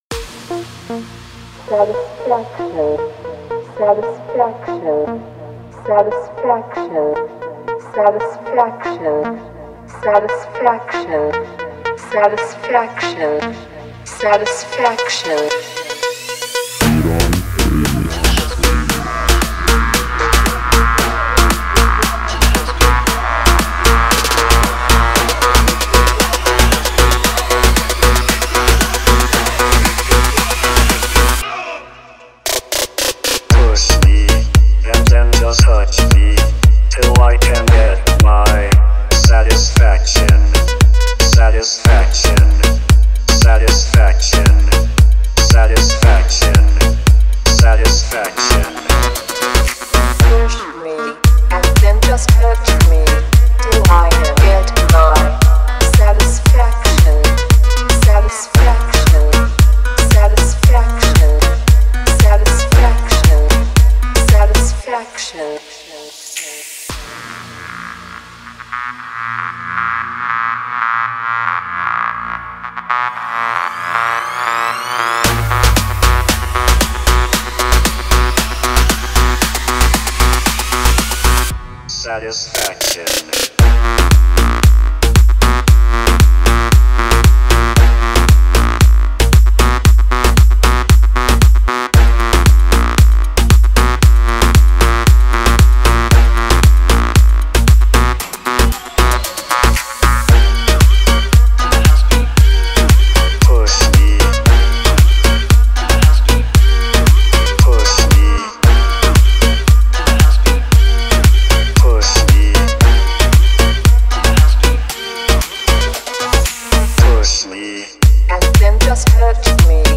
Download house remix for club